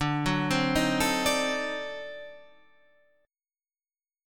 D 7th Flat 9th